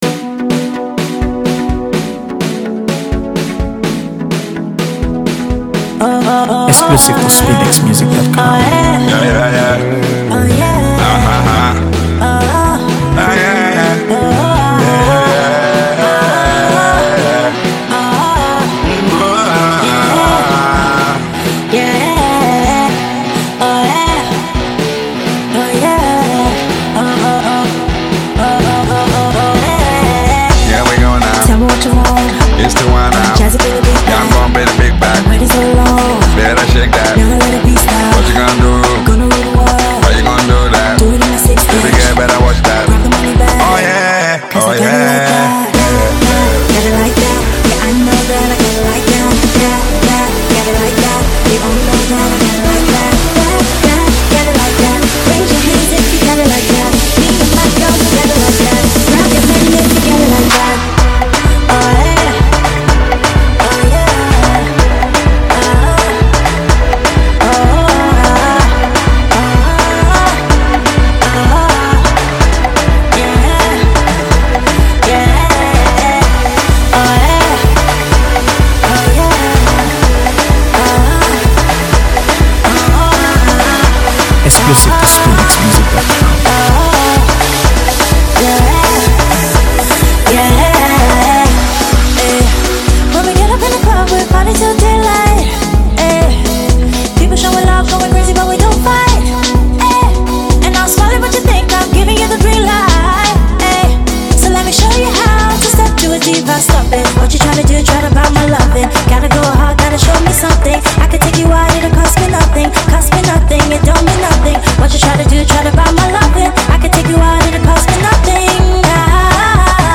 AfroBeats | AfroBeats songs
This upbeat track is part of her debut studio album
fast-paced, infectious tune